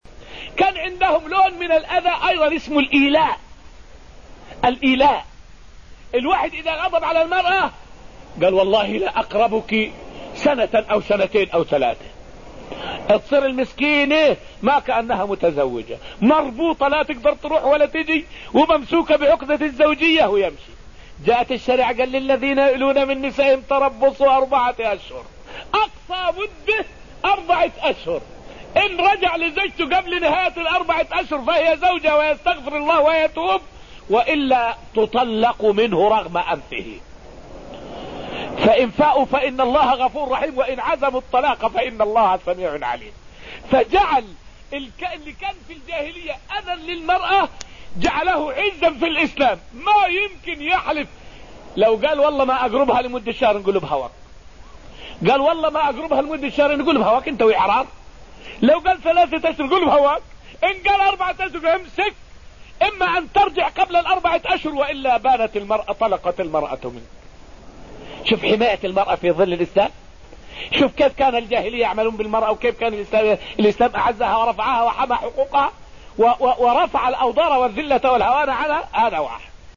فائدة من الدرس الأول من دروس تفسير سورة المجادلة والتي ألقيت في المسجد النبوي الشريف حول الإيلاء من صور إيذاء المرأة في الجاهلية.